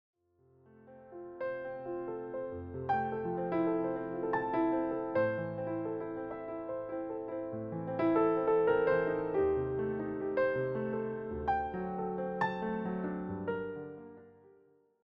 a consistent, relaxed romantic mood